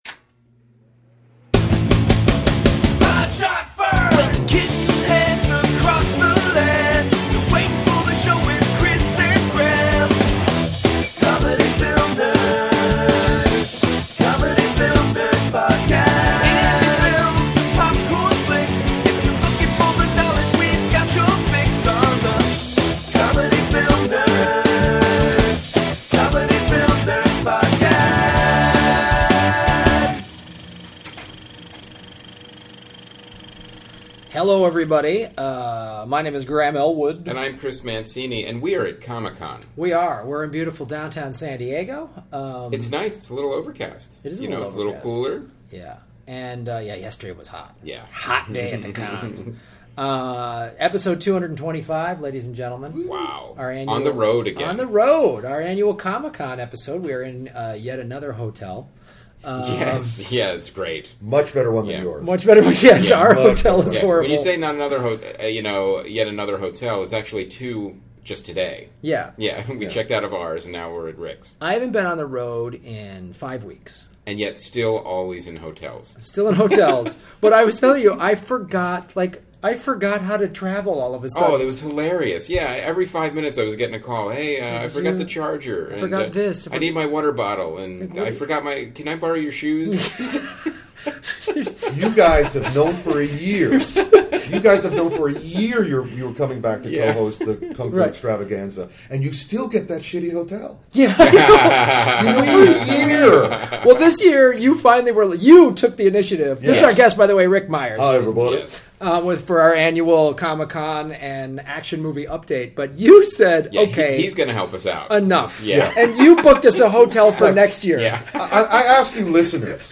LIVE FROM COMIC-CON!